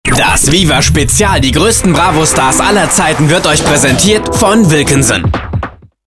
deutscher Profi Sprecher. Off-Sprecher, Werbesprecher, Stationvoice
Kein Dialekt
Sprechprobe: Sonstiges (Muttersprache):